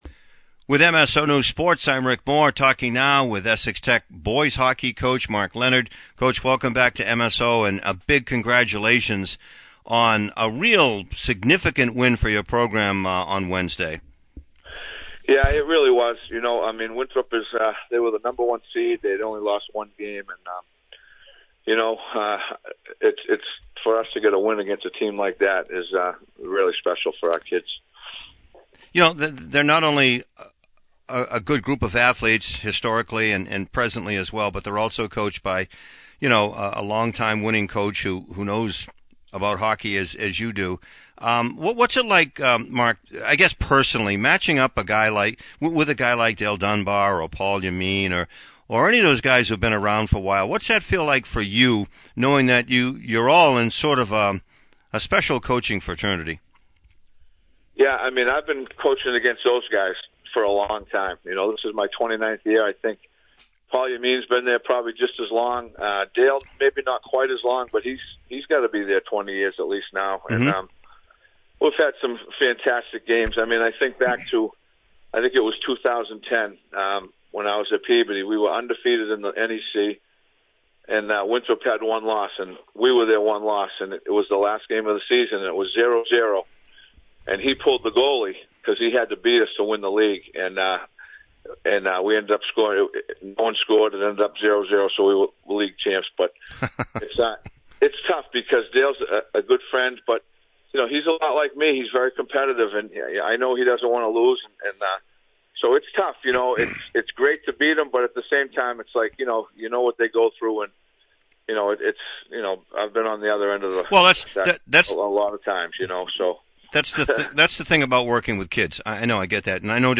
(Audio) Post-game, Pre-game